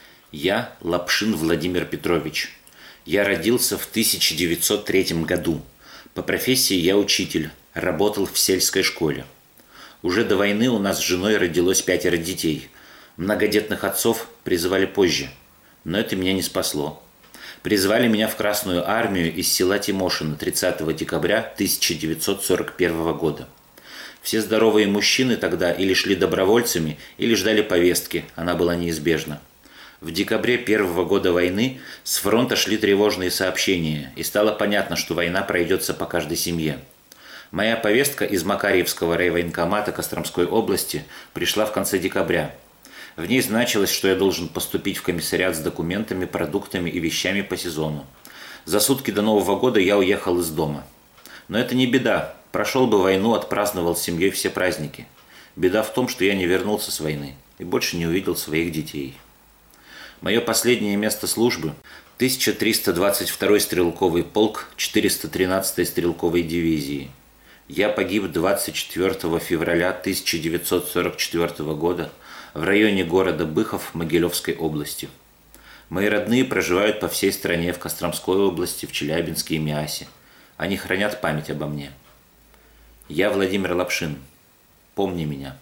# Читает Аудиозапись